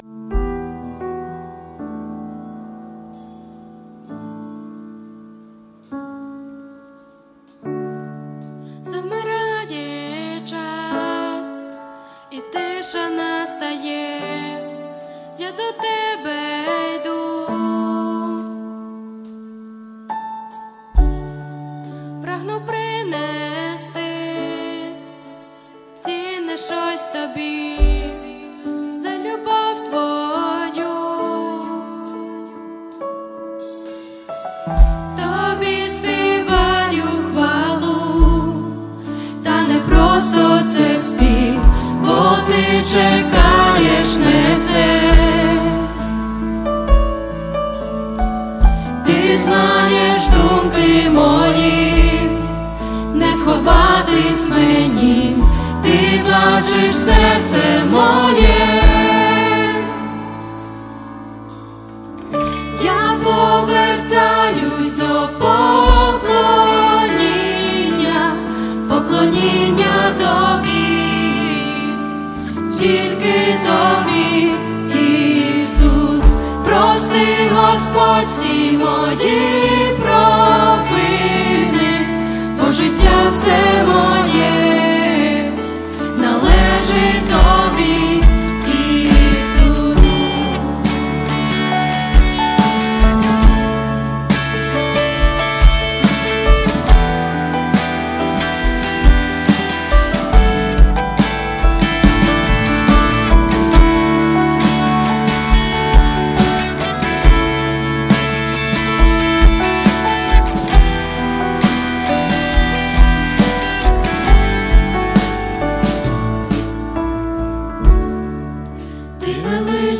397 просмотров 210 прослушиваний 9 скачиваний BPM: 70